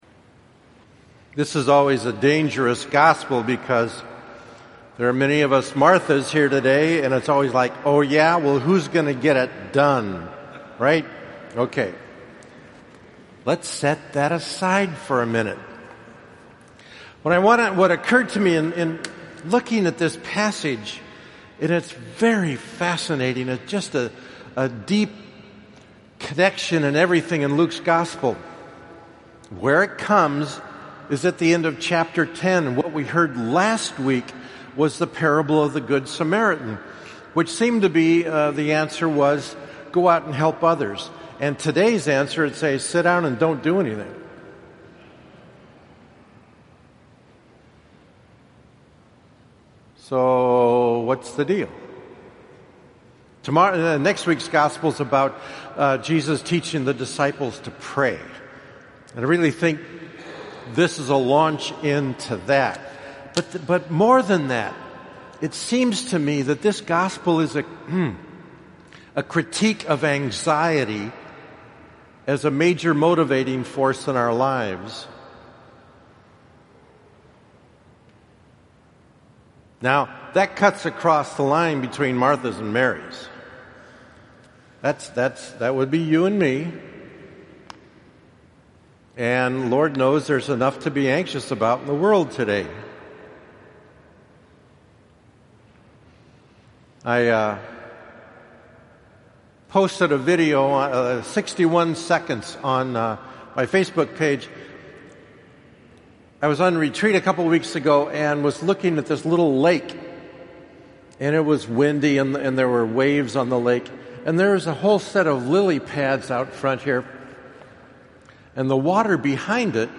Here's the audio of my homily on Martha & Mary.